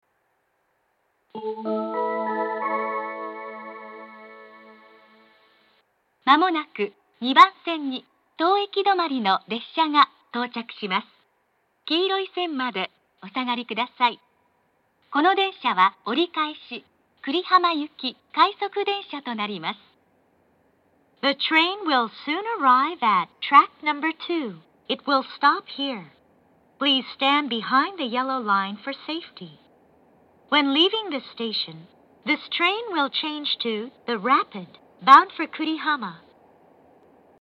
２番線接近放送 折り返し快速久里浜行の放送です。
２番線発車メロディー 曲は「希望の朝」です。
narita-airport-2bannsenn-sekkinn1.mp3